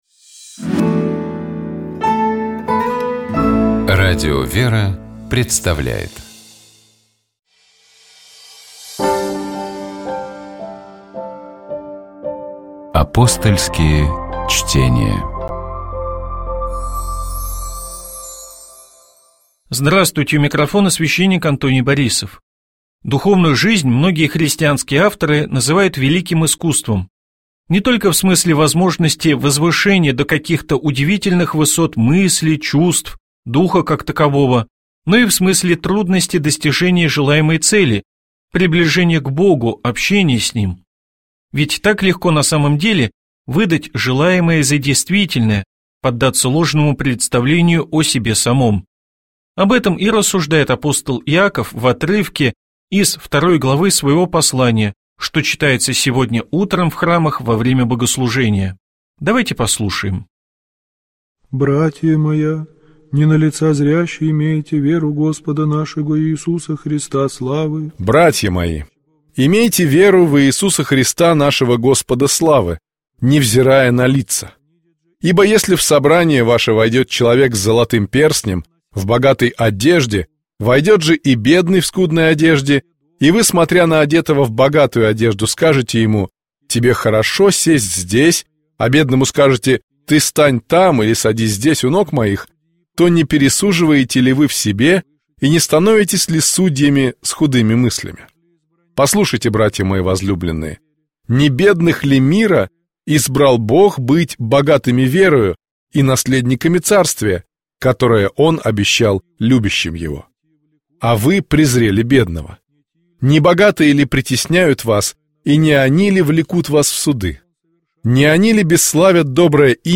Апостольские чтения
Комментирует священник